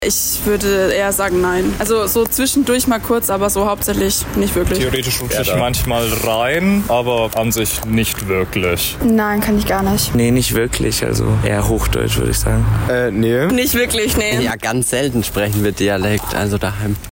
Ganz klar, das war frängggischer Dialekt. Sprecht ihr den noch?
Umfrage-Sprecht-ihr-noch-Dialekt-3.mp3